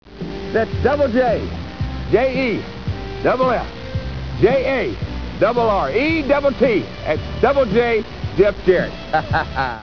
And then he’d drawl out his tag line: